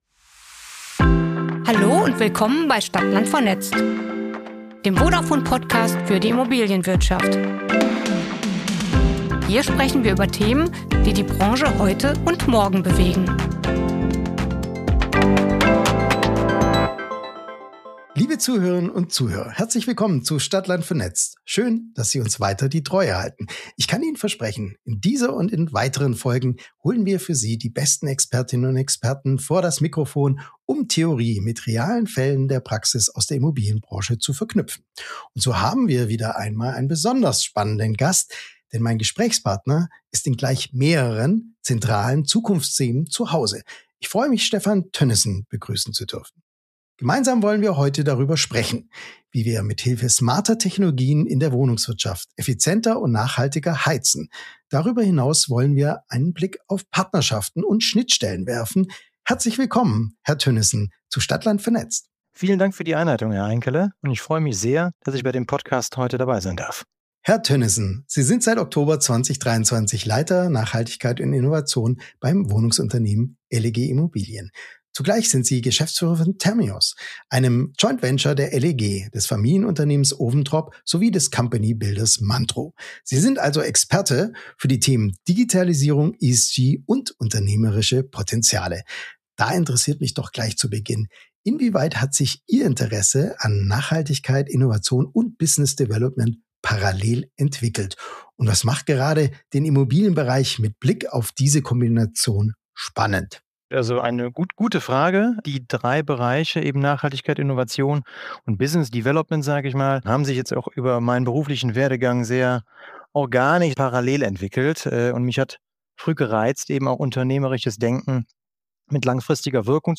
Ein praxisnahes Gespräch über Digitalisierung als Instrument und Impulsgeber – und darüber, warum ohne Daten keine Dekarbonisierung möglich ist.